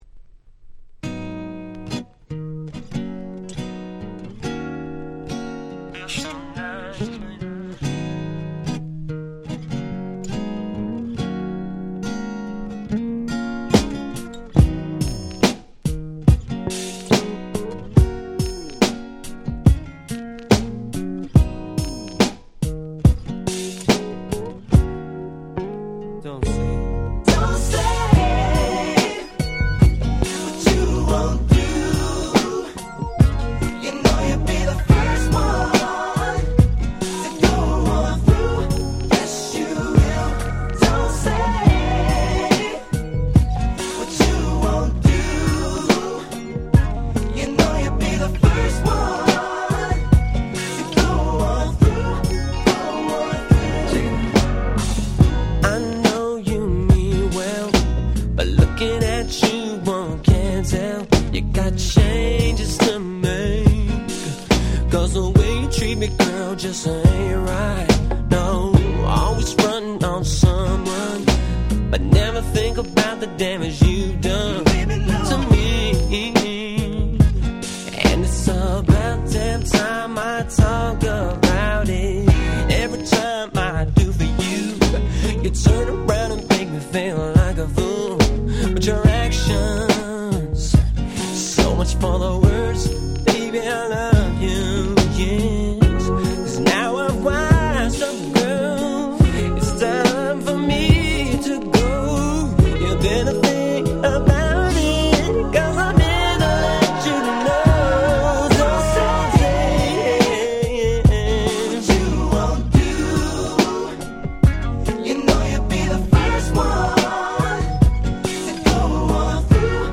97' Smash Hit R&B / Slow Jam !!
まったりとしたNeo Soulで本当に最高。